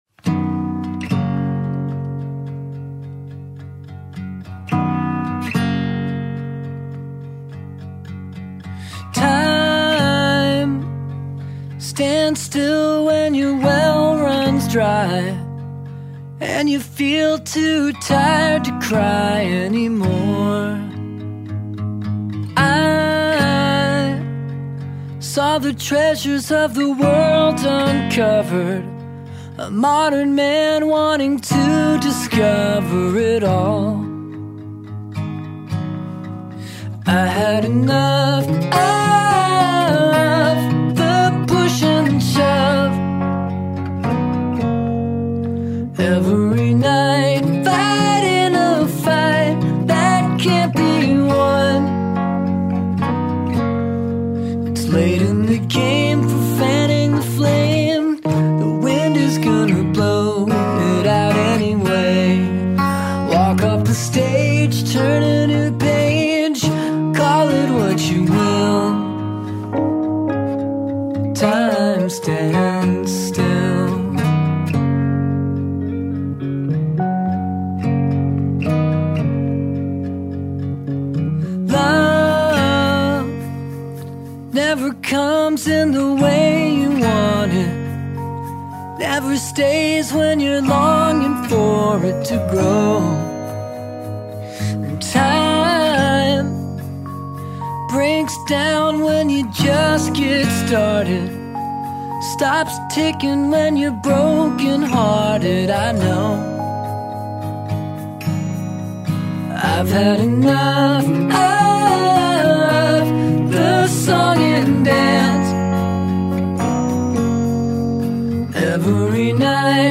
indie singer-songwriter